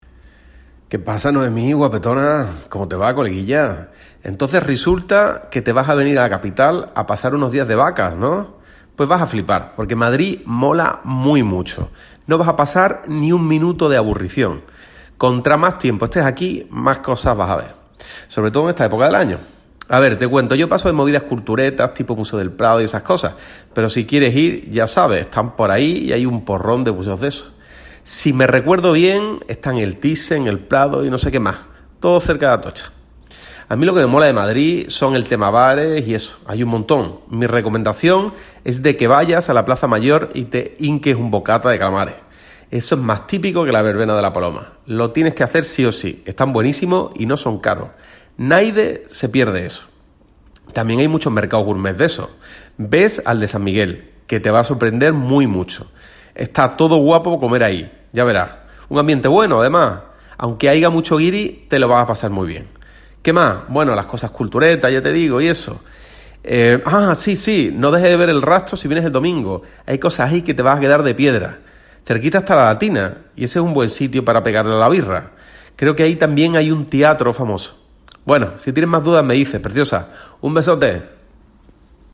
1.1. Imagina la siguiente situación: vas a hacer un viaje de ocio a Madrid y le has pedido a un amigo que tienes allí que te dé algunos consejos sobre qué visitar en la ciudad. Este amigo te ha contestando mandándote un mensaje de voz, que puedes escuchar en el display que aparece más abajo.